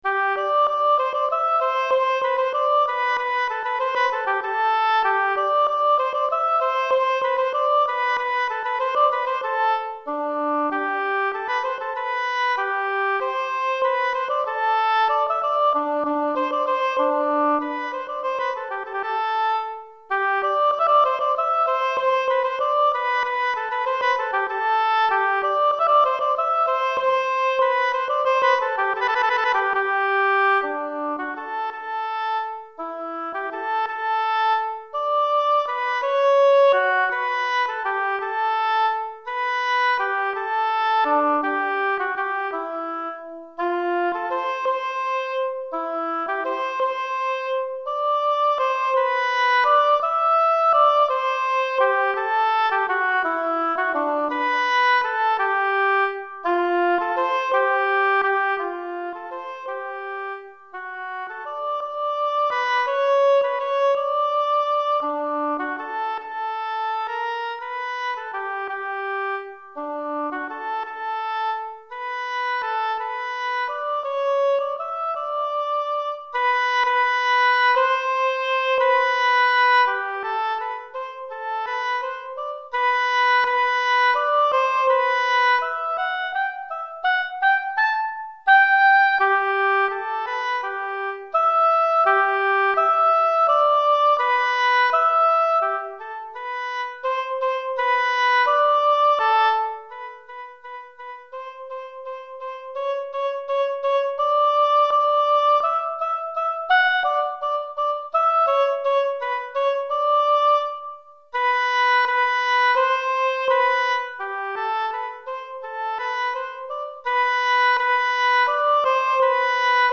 Hautbois Solo